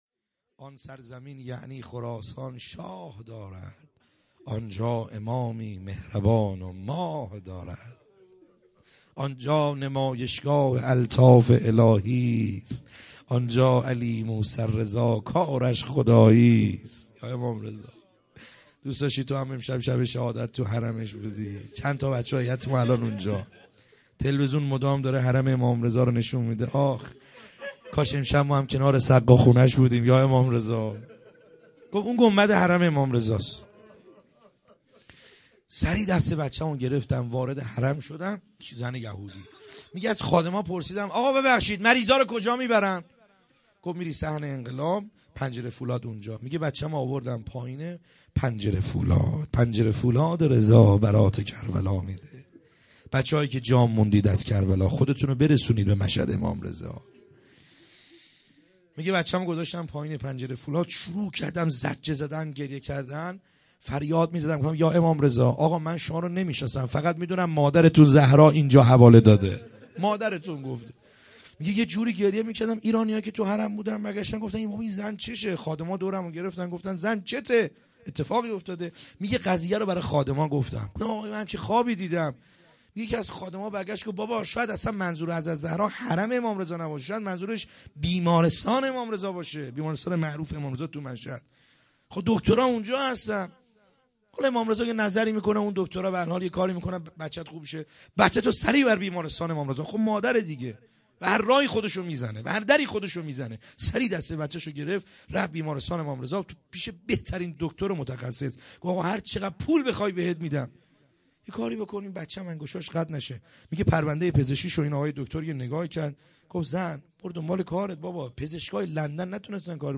خیمه گاه - بیرق معظم محبین حضرت صاحب الزمان(عج) - روضه | امام رضا علیه السلام